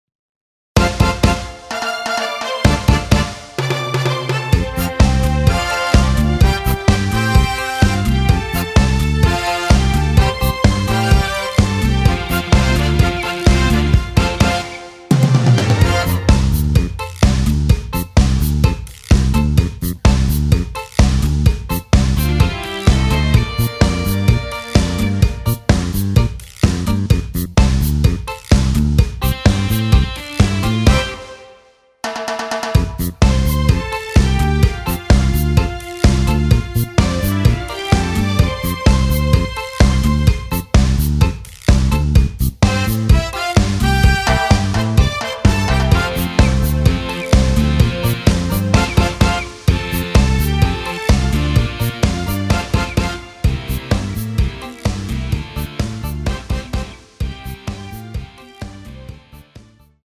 원키에서 (+3)올린 MR 입니다.
앞부분30초, 뒷부분30초씩 편집해서 올려 드리고 있습니다.
중간에 음이 끈어지고 다시 나오는 이유는